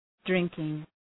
Προφορά
{‘drıŋkıŋ}